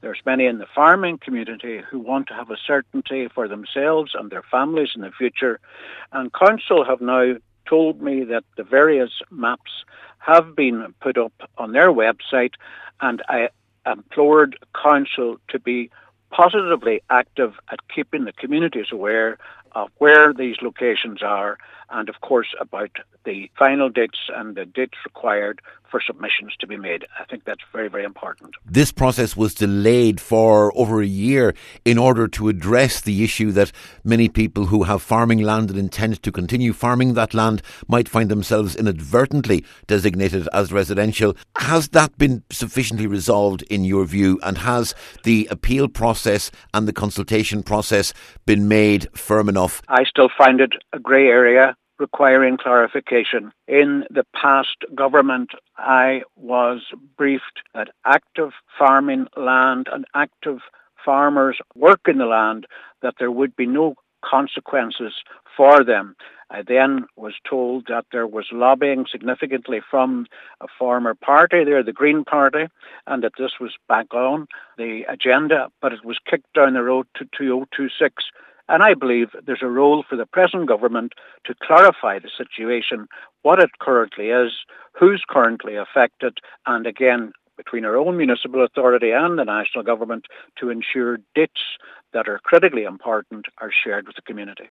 Cllr Albert Doherty says people have the right to appeal a designation they believe to be wrong, and says the council must ensure members of the public can easily find and view the maps: